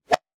metahunt/weapon_bullet_flyby_08.wav at dfc221d77e348ec7e63a960bbac48111fd5b6b76
weapon_bullet_flyby_08.wav